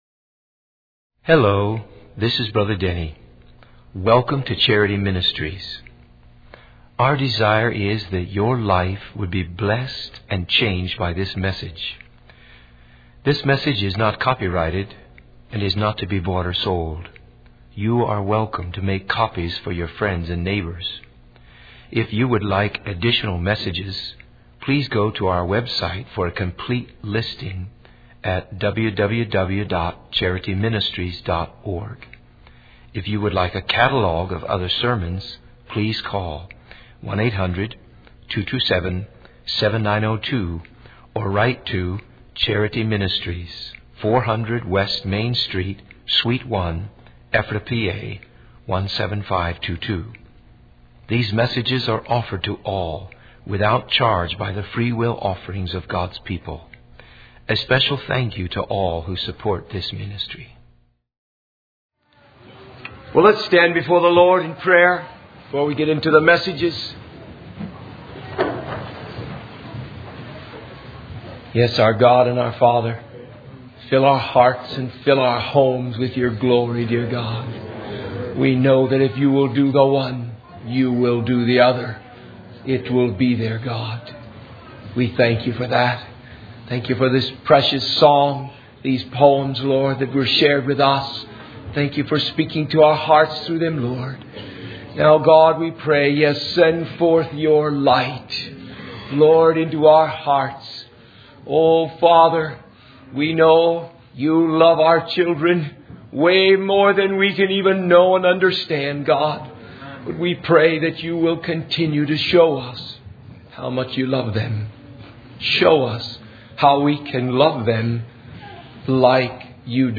In this sermon, the speaker discusses the common behavior of people when they receive a set of tapes on parenting.